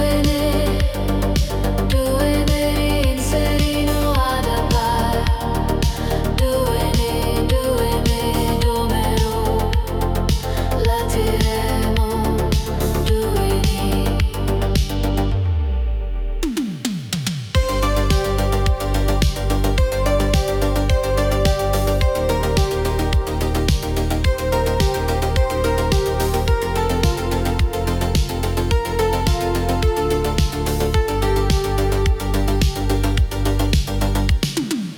Electronic Dance
Жанр: Танцевальные / Электроника